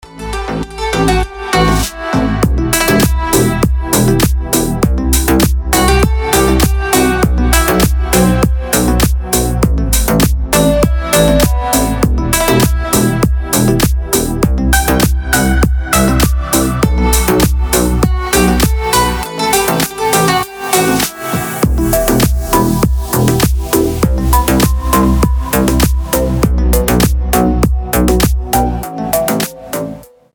• Качество: 320, Stereo
deep house
восточные мотивы
без слов
красивая мелодия
струнные